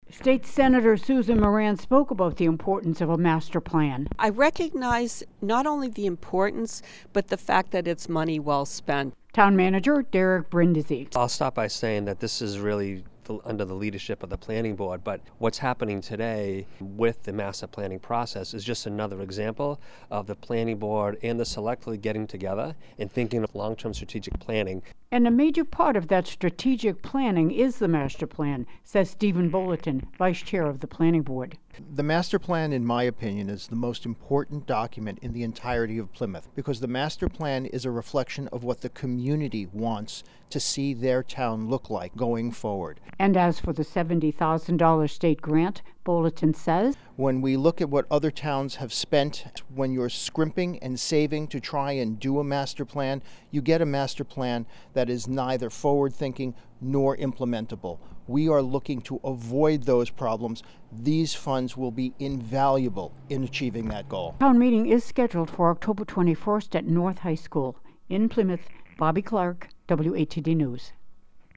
In Plymouth, at Town Hall on the 21st, State Senator Susan Moran delivered the goods— $70,000 worth—to help in funding the Master Plan.
State Senator Moran spoke about the importance of a Master Plan: